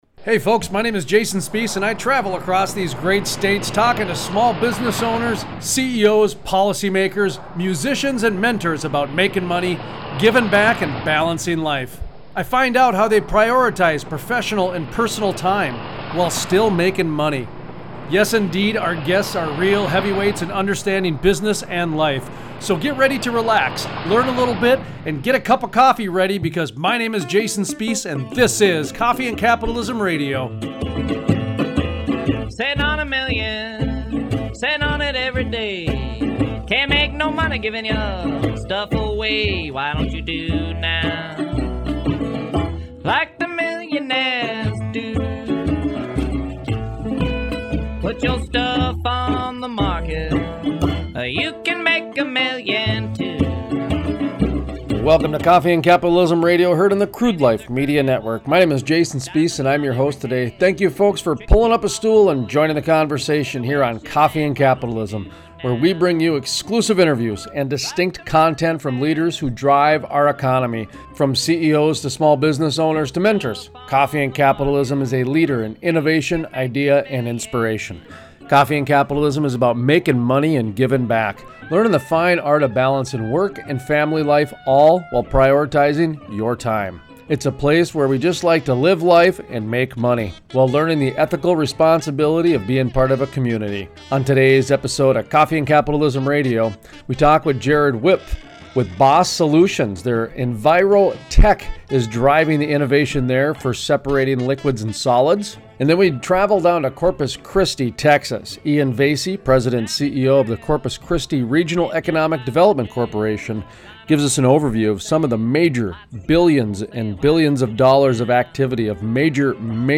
Workplace Wellness Interview